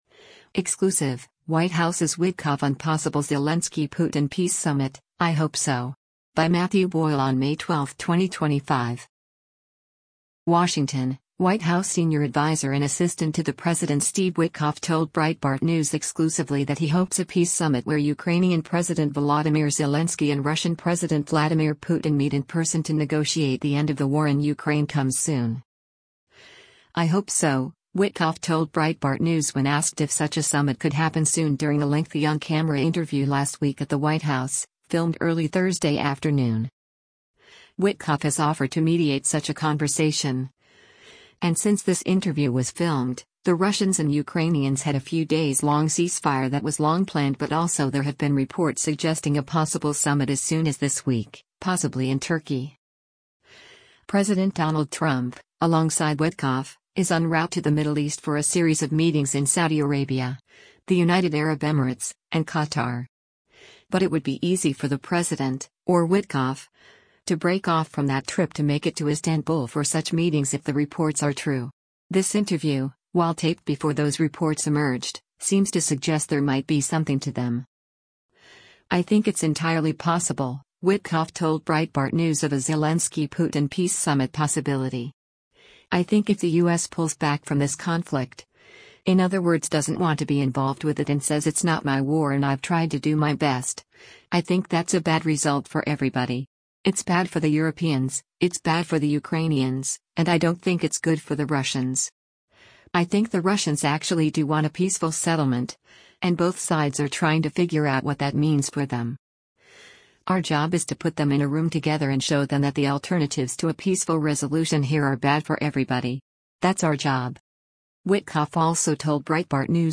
“I hope so,” Witkoff told Breitbart News when asked if such a summit could happen soon during a lengthy on-camera interview last week at the White House, filmed early Thursday afternoon.